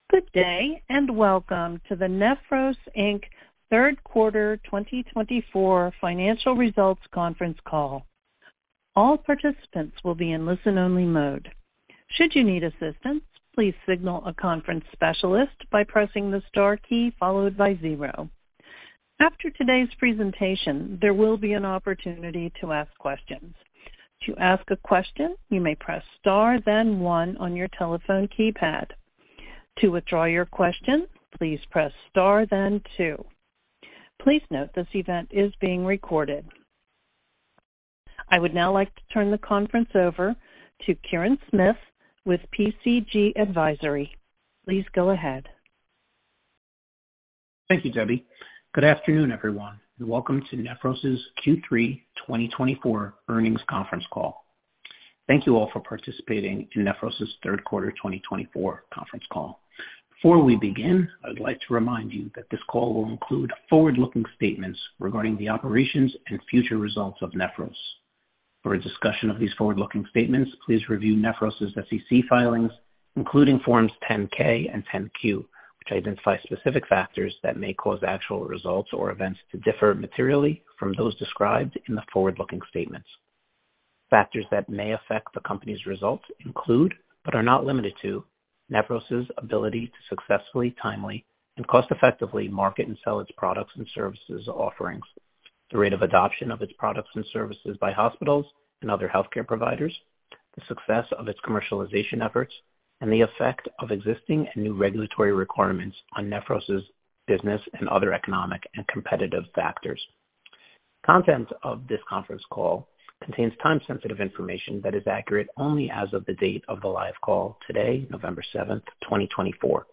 Listen to the replay of the Q3 2024 conference call